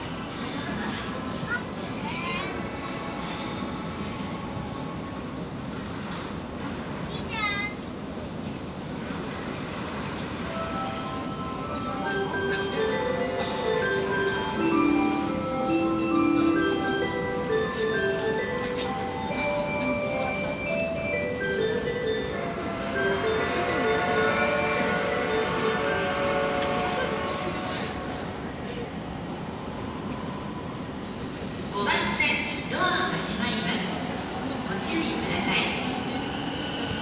品川駅　5番線（東海道線） 発車メロディー
鉃道唱歌の発車メロディーです。車内で録音したので聞きにくいかも…。フル録音しました。